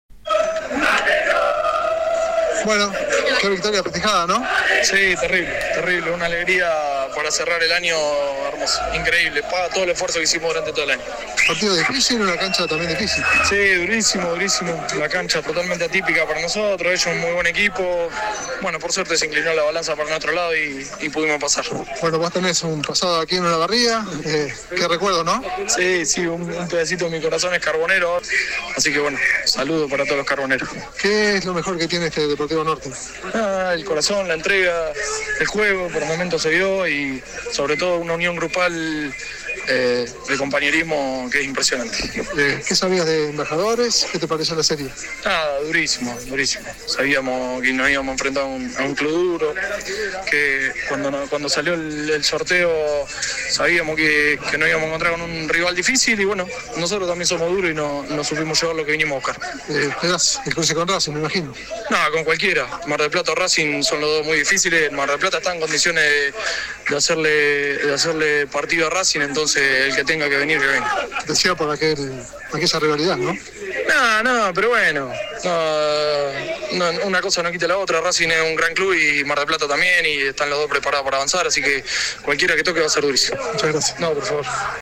Terminado el partido dialogó para «Emblema».
AUDIO DE LA ENTREVISTA